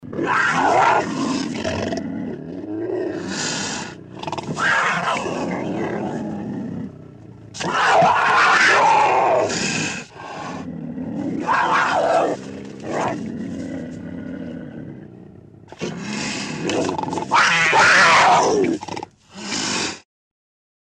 Хорошие варианты звуков рычания и шипения дикой пумы в mp3 формате.
3. Рычание
rychanie-pumy.mp3